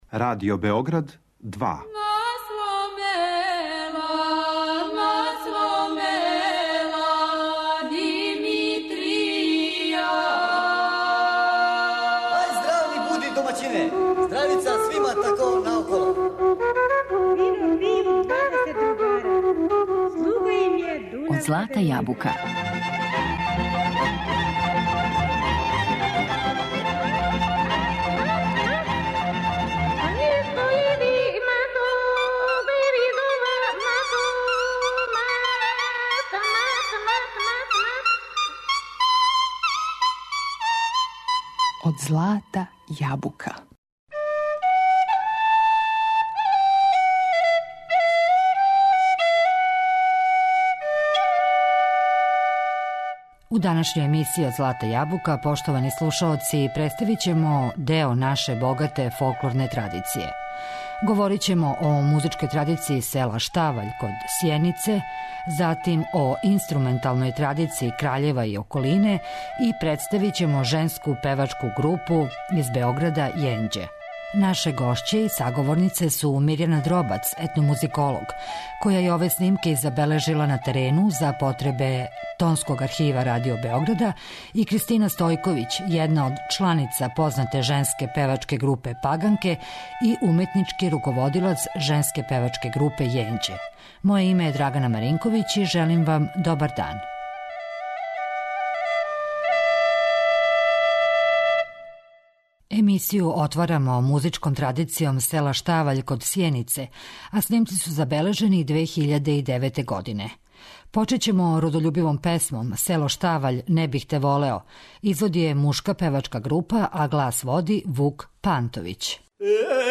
Наше гошће су етномузиколог